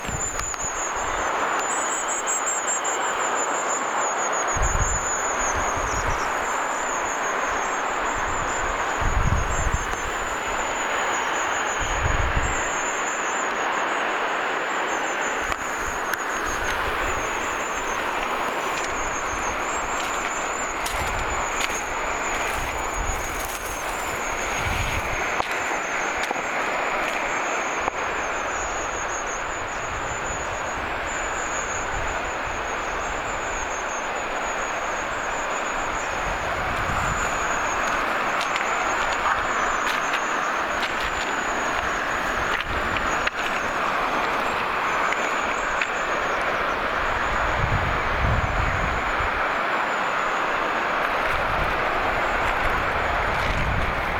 saaressa pyrstötiasia
saaressa_pyrstotiaisia.mp3